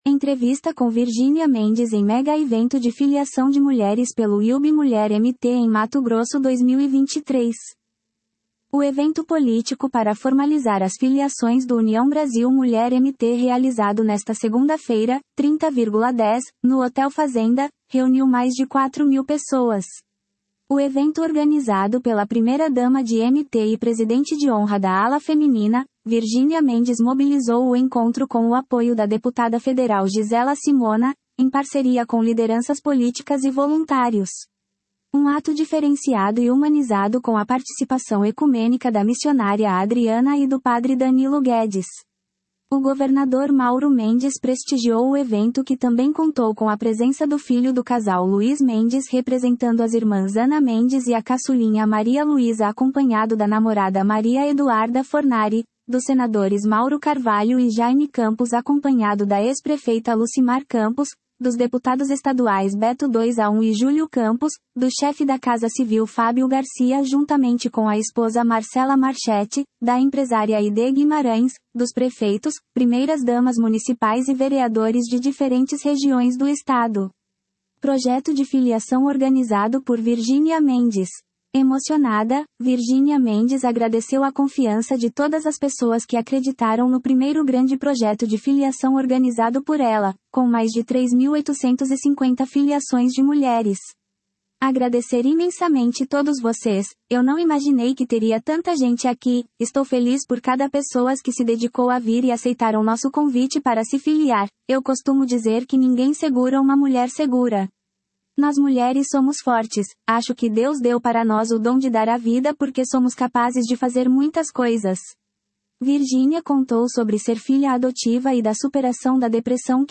Entrevista com Virginia Mendes em megaevento de filiação de mulheres pelo UB Mulher MT em Mato Grosso 2023